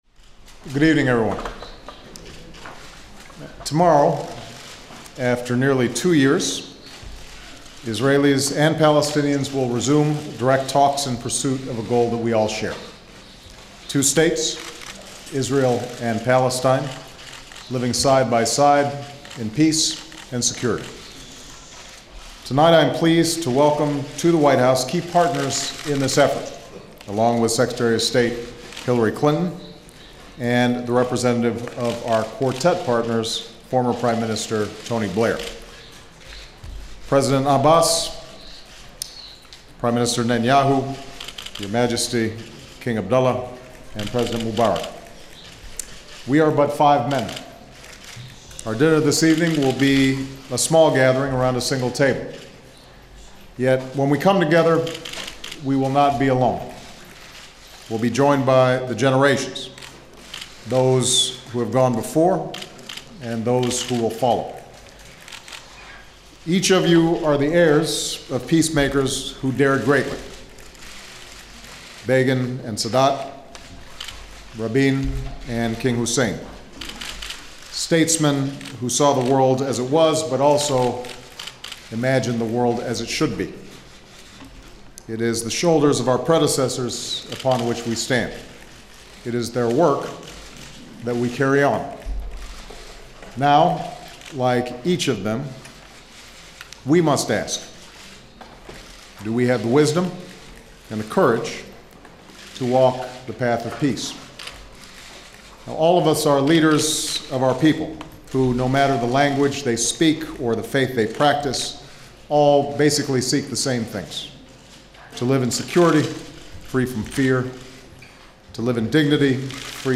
barackobamajointremarks4middleeastleaders.mp3